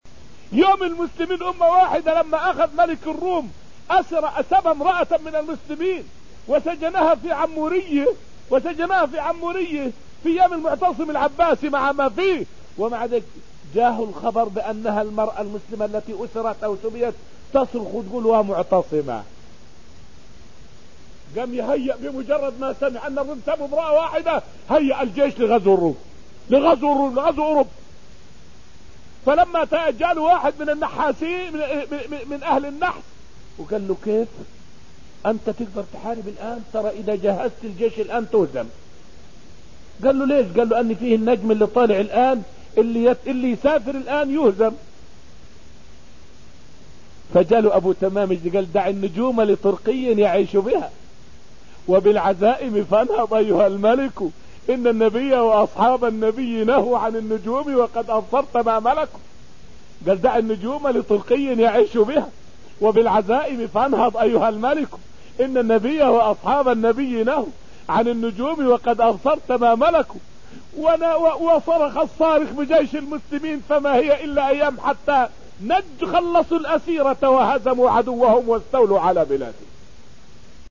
فائدة من الدرس الخامس من دروس تفسير سورة المجادلة والتي ألقيت في المسجد النبوي الشريف حول إغاثة المعتصم للمرأة المسلمة.